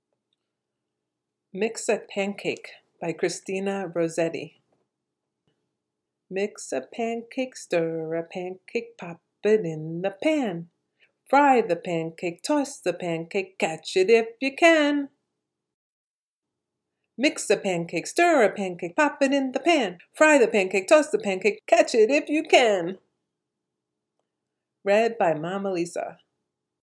Chansons enfantines anglaises